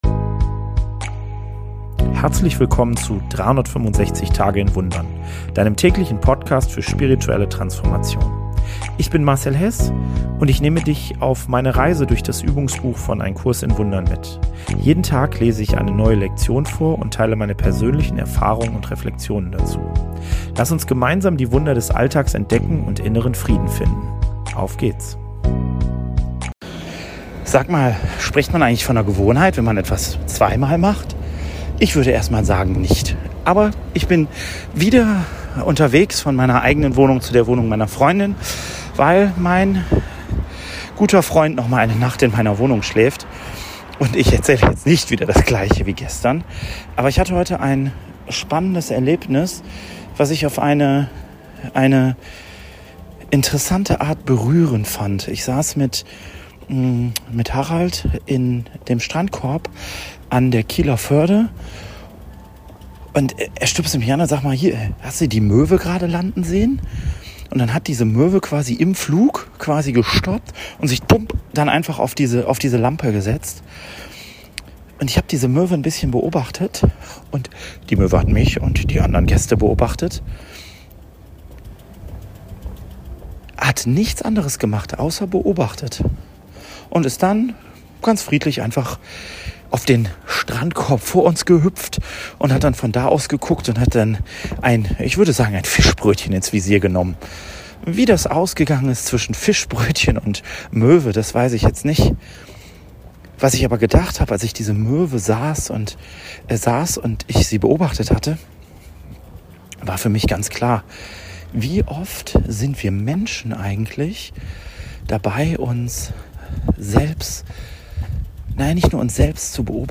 Während eines nächtlichen Spaziergangs spreche ich darüber, wie wir